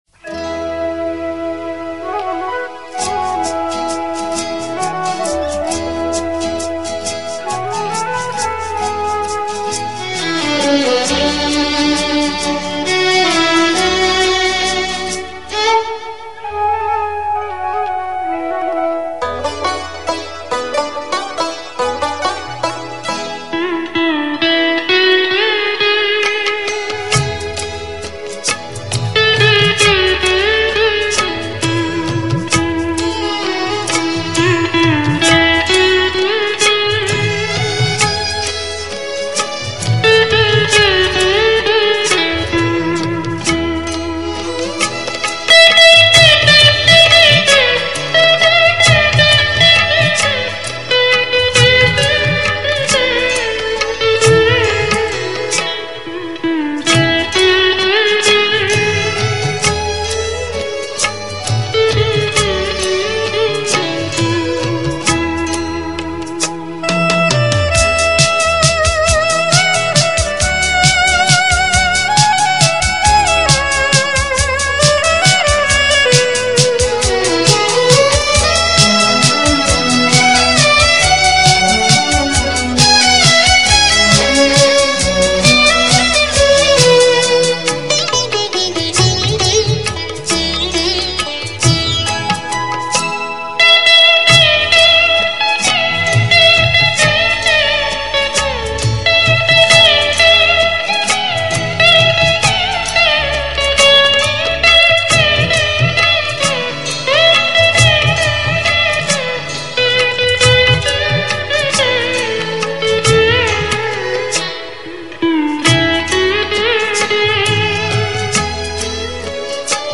Instrumental Songs > Old Bollywood